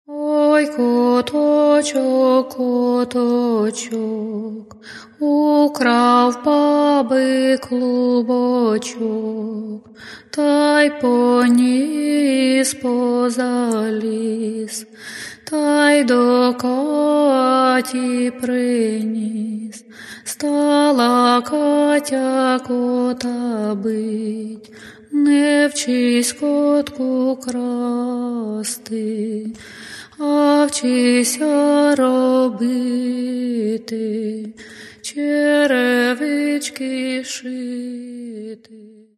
К сожалению, качество записи не безупречно.